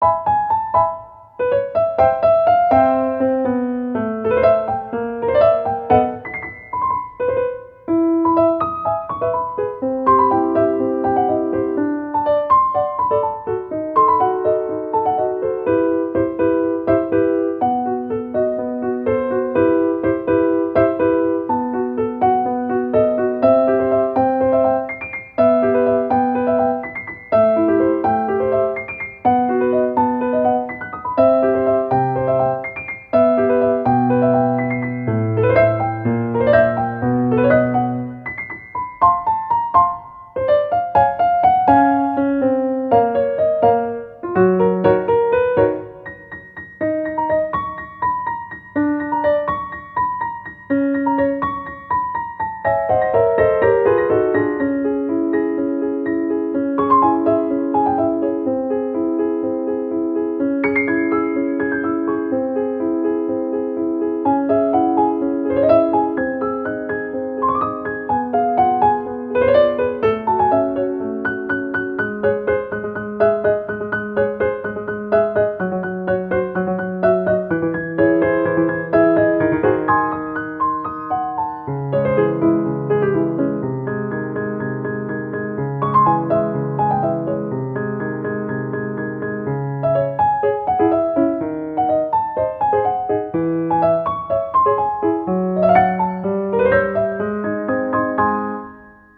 ogg(R) 楽譜 軽やか 不思議 お洒落
不思議でスタイリッシュな楽曲。